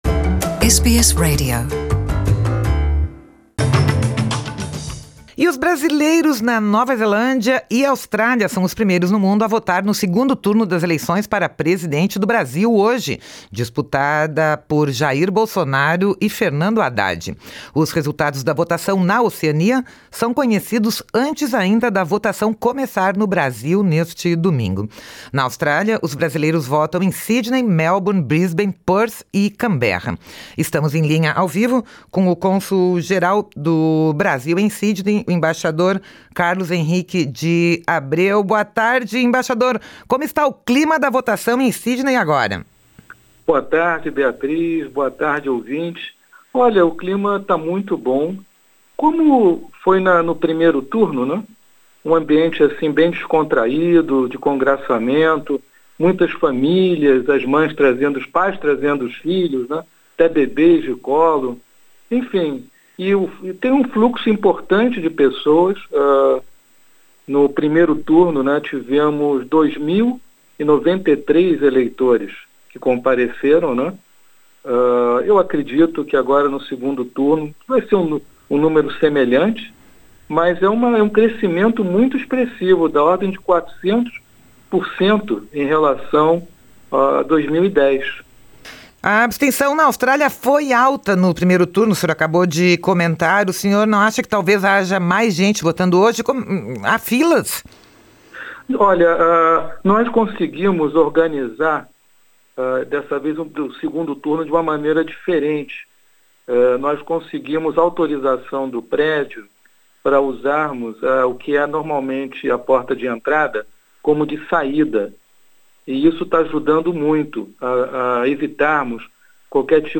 O cônsul-geral do Brasil em Sydney, embaixador Carlos Henrique de Abreu, em entrevista ao vivo ao Programa Português da Rádio SBS, diz que o clima no local de votação é muito tranquilo, melhor organizado e com menos filas do que no primeiro turno.